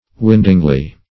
windingly - definition of windingly - synonyms, pronunciation, spelling from Free Dictionary Search Result for " windingly" : The Collaborative International Dictionary of English v.0.48: Windingly \Wind"ing*ly\, adv.